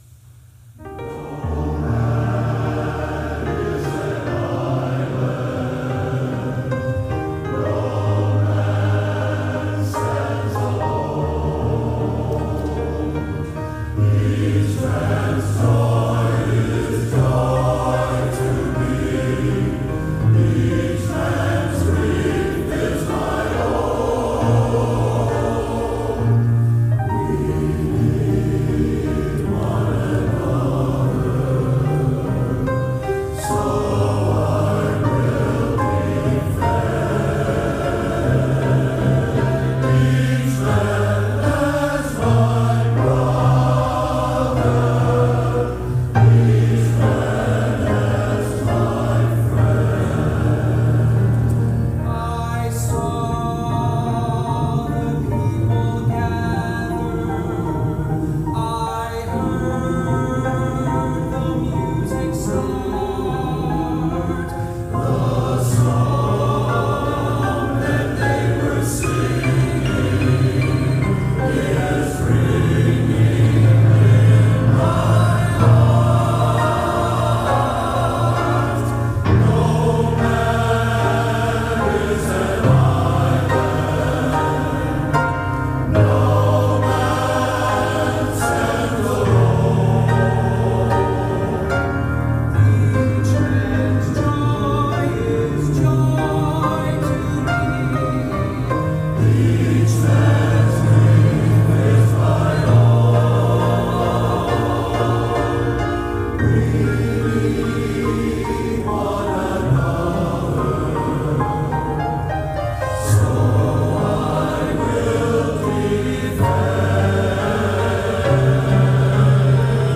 First Congregational Church Of Southington, Connecticut - April 22, 2023